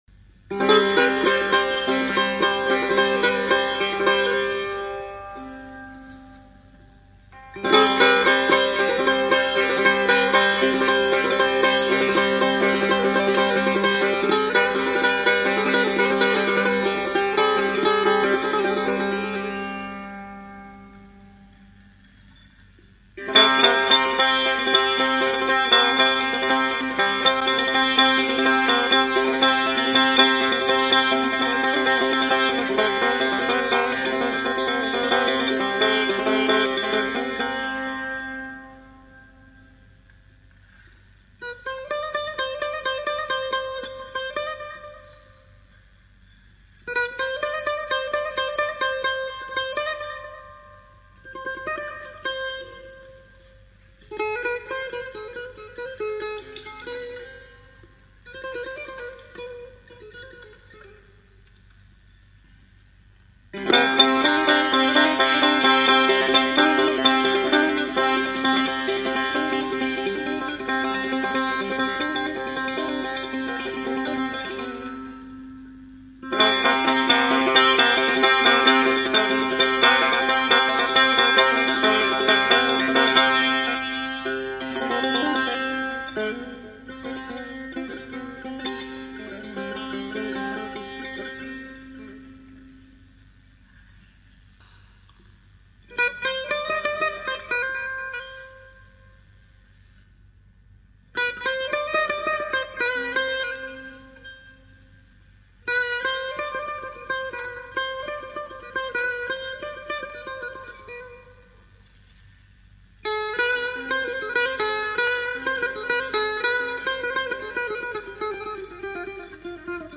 سه تار
این ساز، زهی زخمه ای بوده و در ساخت بدنۀ این ساز از چوب، فلز و سیم استفاده شده است.
این ساز، هم به صورت تک نوازی و هم در گروه نوازی مورد استفاده قرار می گیرد و صدایی حزین دارد.
سه-تار-online-audio-converter.com_.mp3